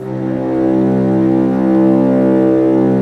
Index of /90_sSampleCDs/Roland - String Master Series/STR_Vcs Bow FX/STR_Vcs Sordino